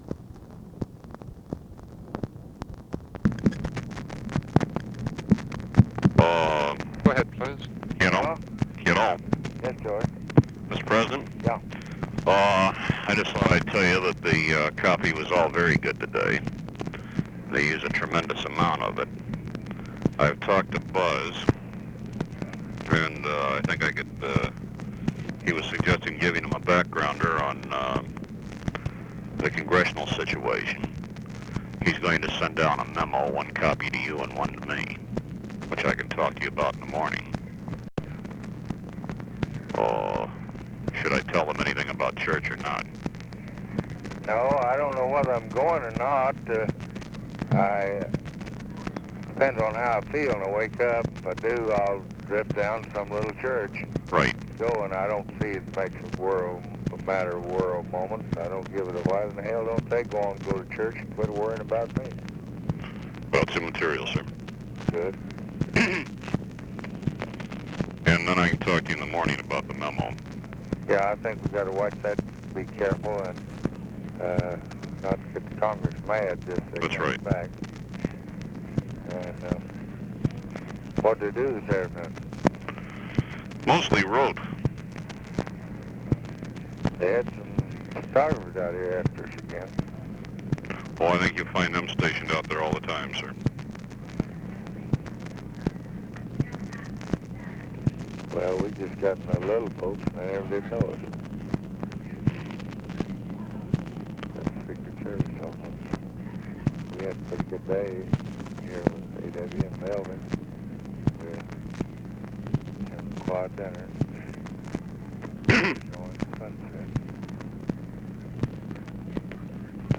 Conversation with GEORGE REEDY, July 19, 1964
Secret White House Tapes